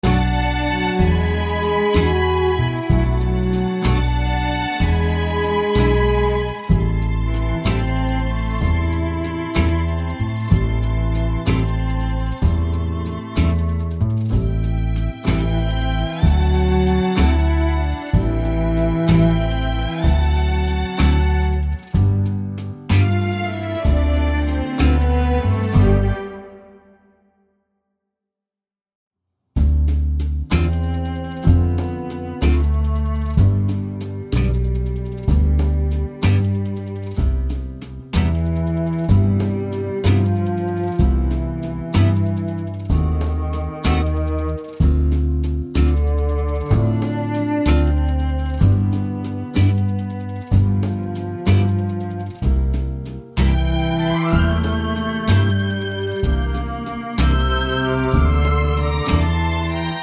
no Backing Vocals Jazz / Swing 2:28 Buy £1.50